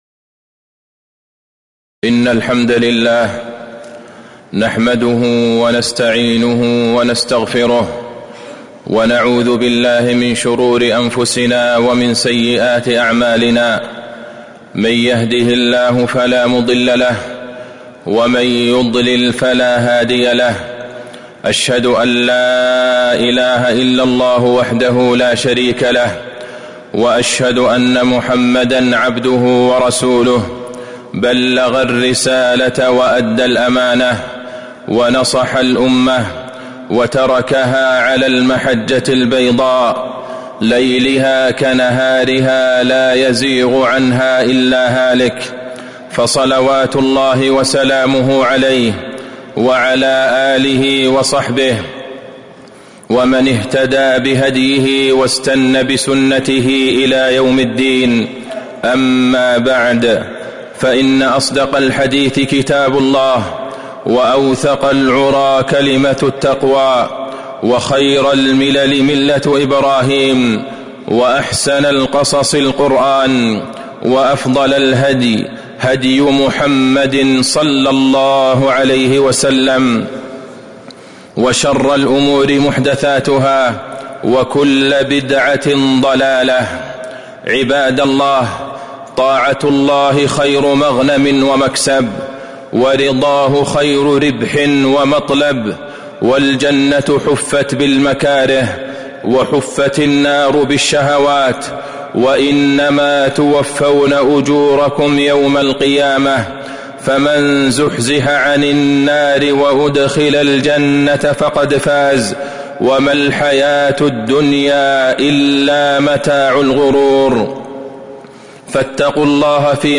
تاريخ النشر ٢٥ ذو القعدة ١٤٤٦ هـ المكان: المسجد النبوي الشيخ: فضيلة الشيخ د. عبدالله بن عبدالرحمن البعيجان فضيلة الشيخ د. عبدالله بن عبدالرحمن البعيجان الاستعداد لاستقبال موسم الحج